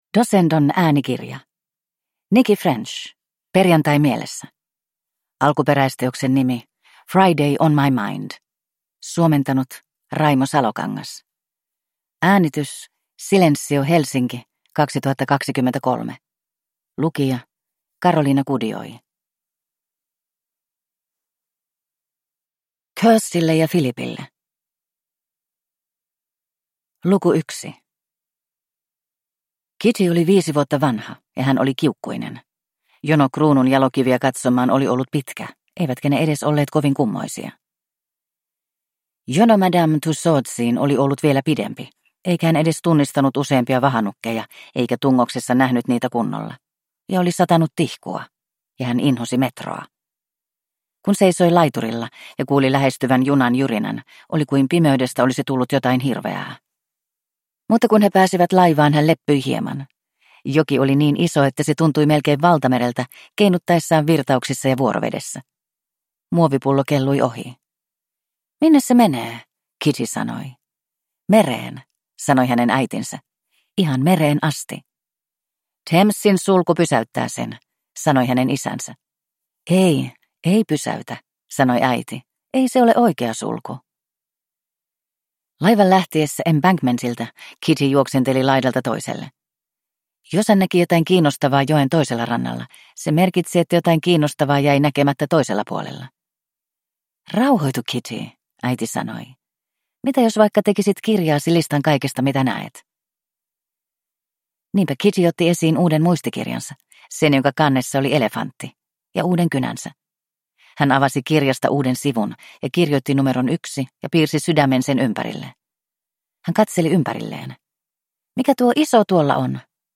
Perjantai mielessä (ljudbok) av Nicci French